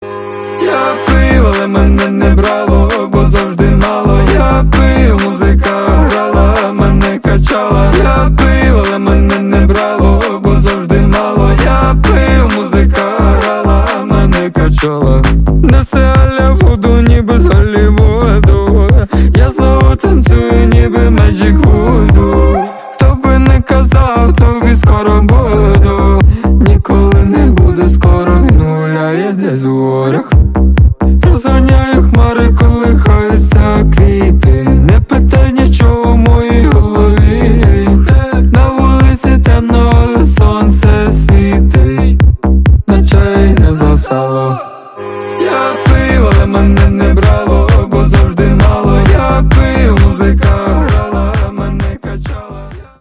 • Качество: 128, Stereo
поп
ритмичные
веселые
танцевальная музыка
Club Dance